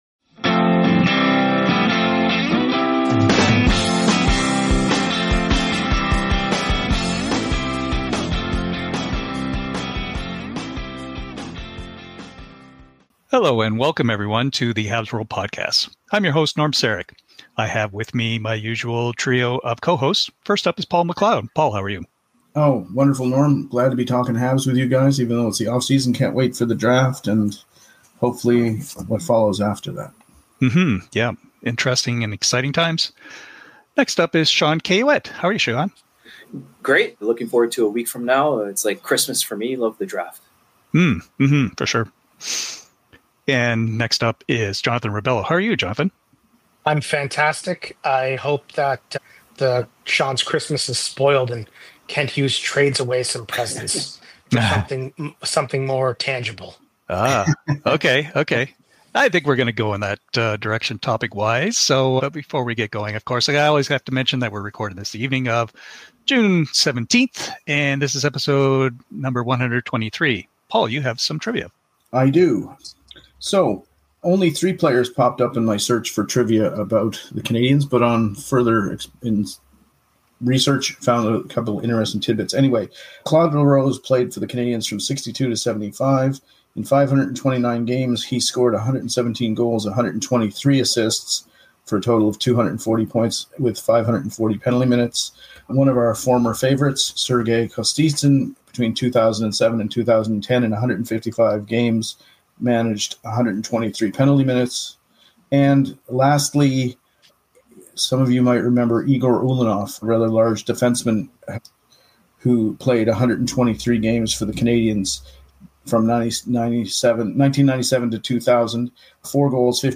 On the evening of Game 6 of the Stanley Cup Final, the HabsWorld podcast crew returned to talk about the Montreal Canadiens in a special NHL Draft podcast.